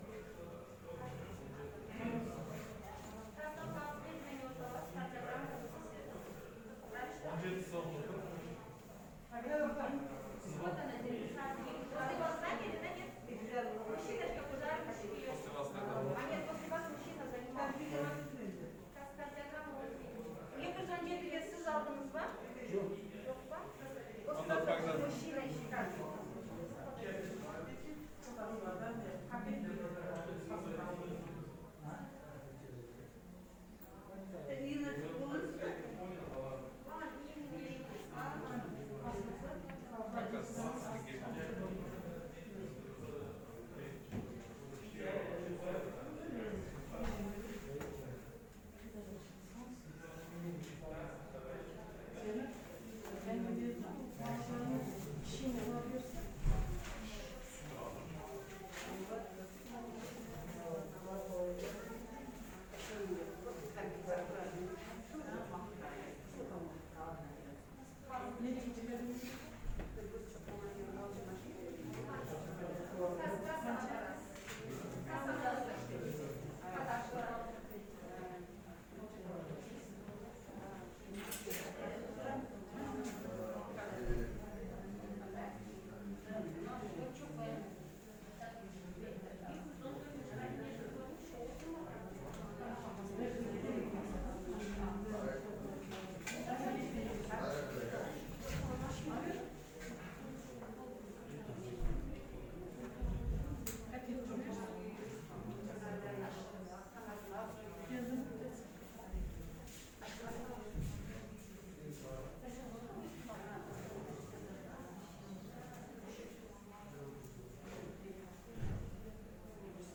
Звуки поликлиники
Скачивайте или слушайте онлайн фон шума людей в поликлинике: обрывки разговоров в очереди, шаги по коридорам, детский плач, голоса из динамика.